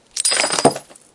破碎的玻璃 " 破碎的玻璃 12
描述：一个原始音频mp3录制的人扔在外面的水泥玻璃和它粉碎。包括一些风的背景噪音。用黑色Sony IC录音机录制。